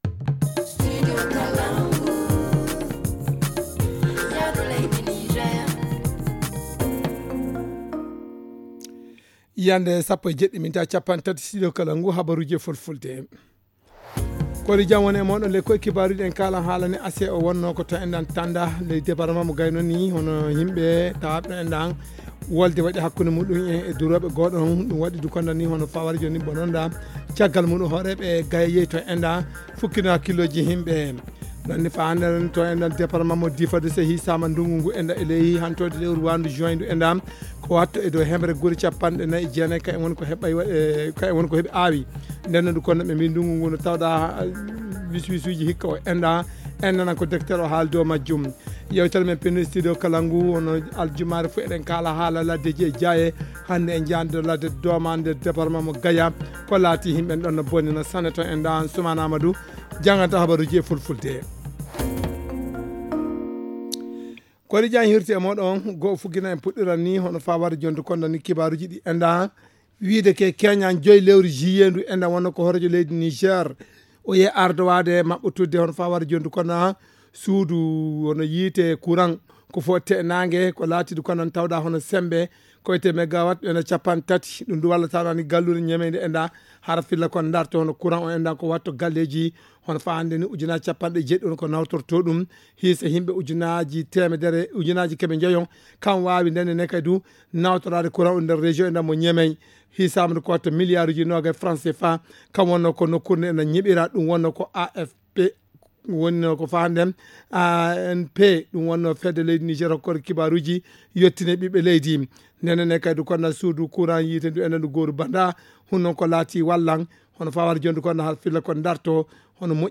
Le journal du 6 juillet 2023 - Studio Kalangou - Au rythme du Niger